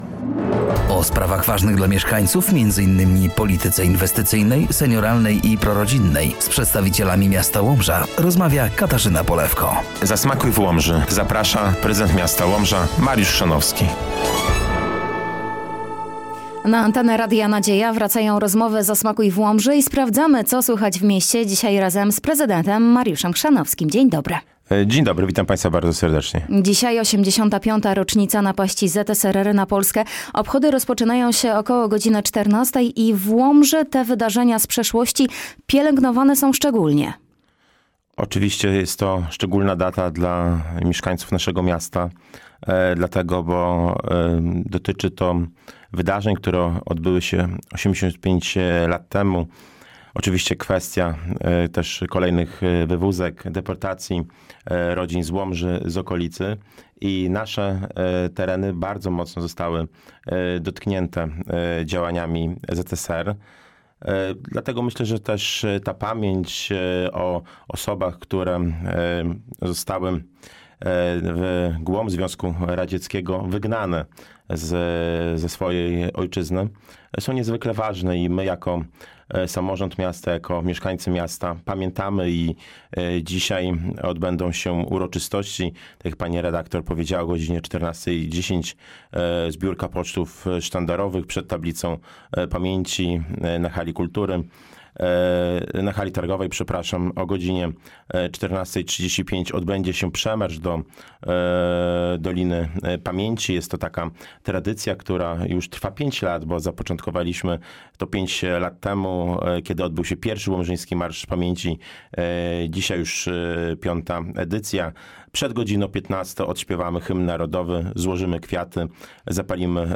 Studio Radia Nadzieja odwiedził Prezydent Miasta, Mariusz Chrzanowski.